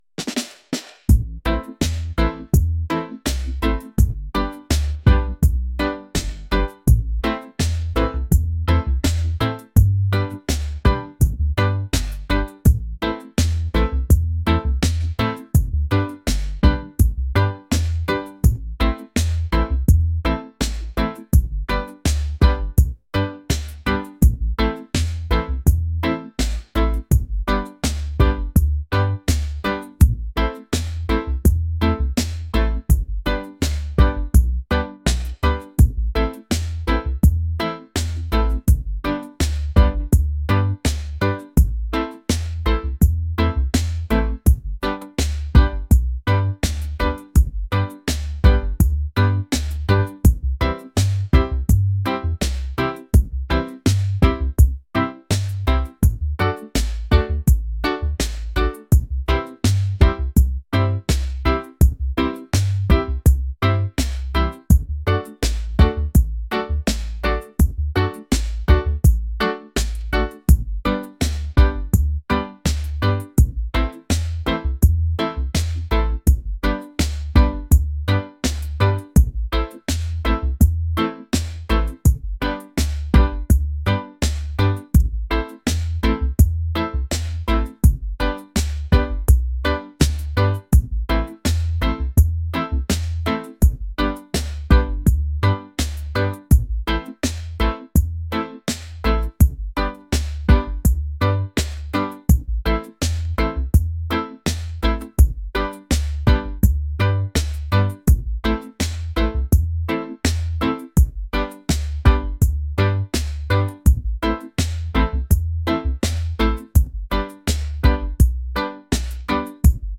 smooth | laid-back | reggae